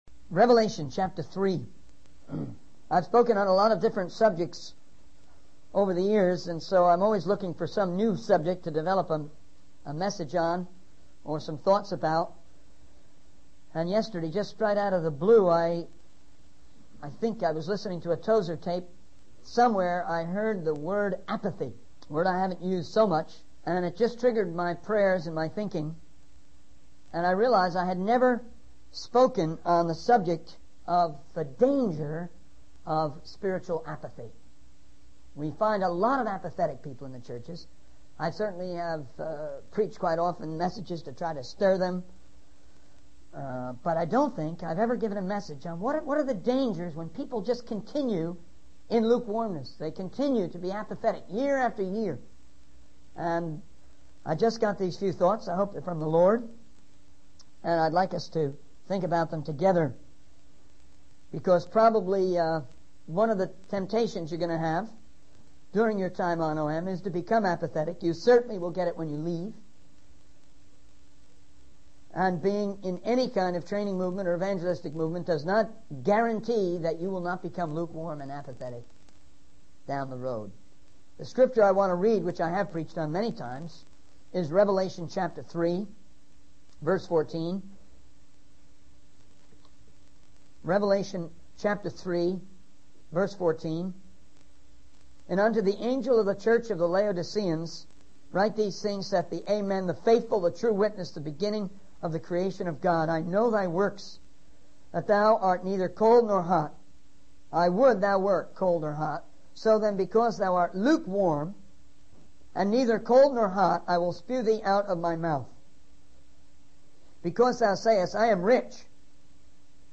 In this sermon, the speaker shares a personal experience of discouragement and relates it to the concept of spiritual apathy. He uses the analogy of salmon swimming upstream to illustrate the need for Christians to go against the tide of apathy in society.